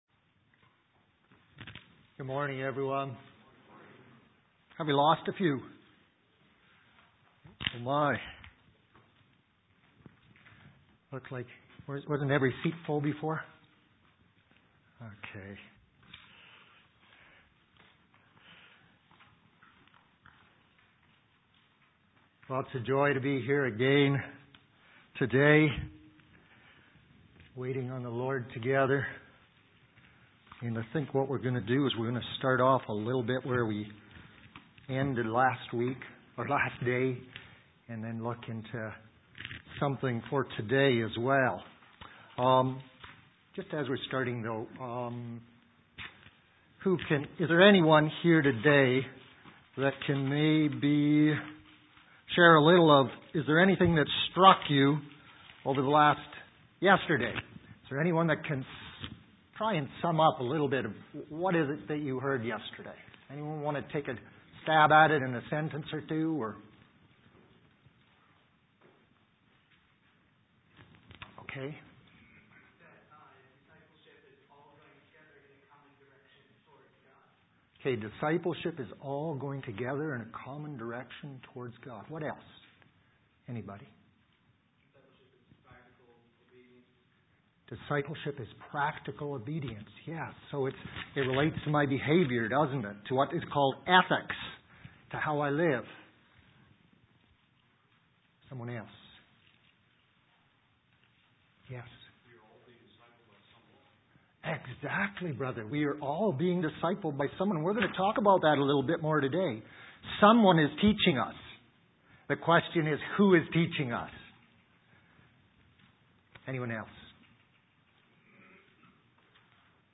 Sermon set